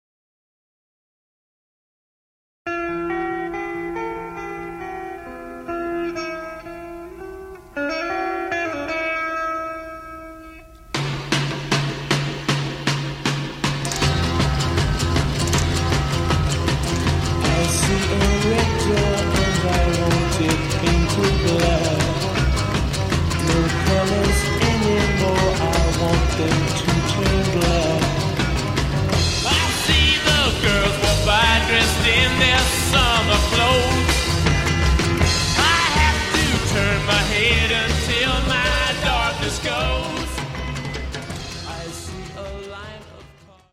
of sitar strum – drums!